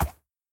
horse_soft3.ogg